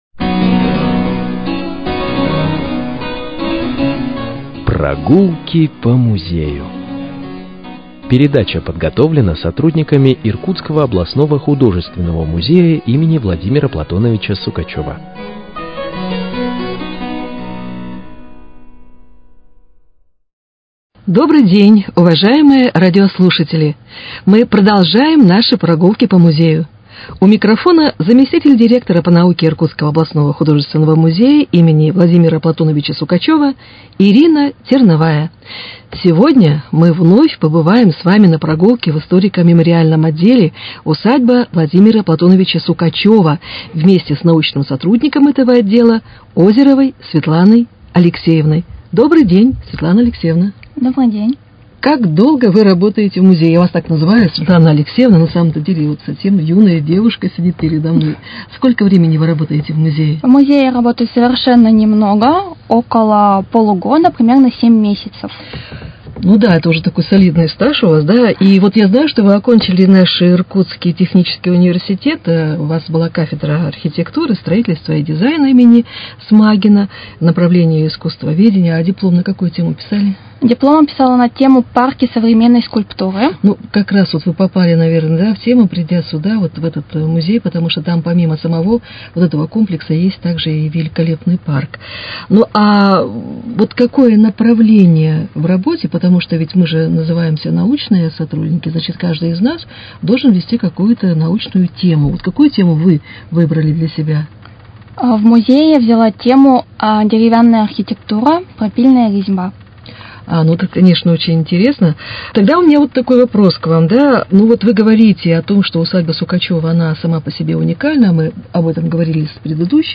Прогулки по музею: Беседа с сотрудником историко-мемориального отделом музея – Усадьбы Сукачева